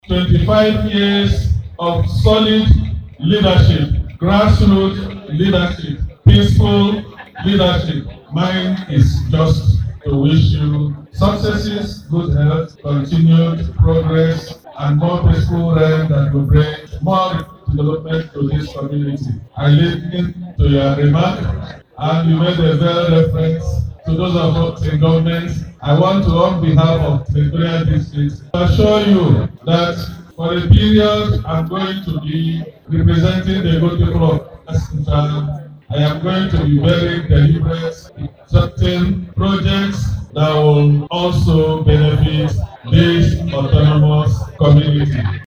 Also speaking, the Senator representing Abia Central Senatorial District Colonel Austin Akobundu wished the monarch more years in good health, adding that he would collaborate with the relevant bodies to attract developmental projects to Ugwu Ibere autonomous community.